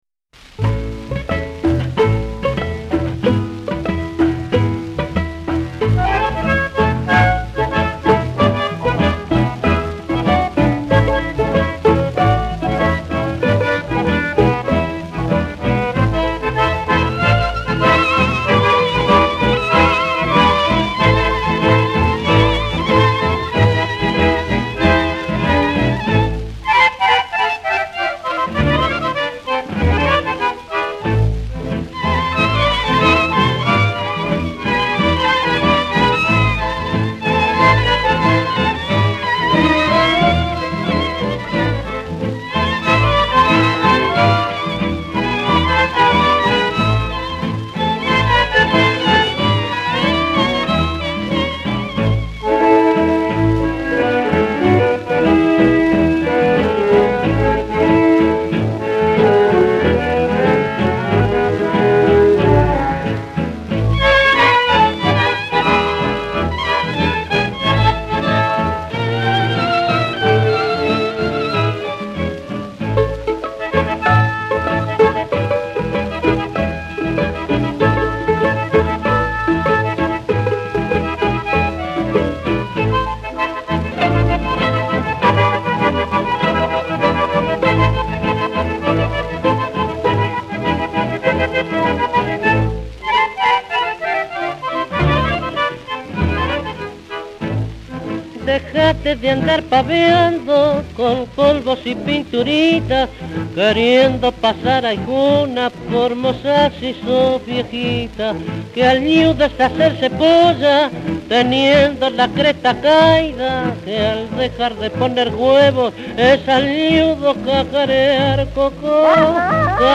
Genre: Milonga.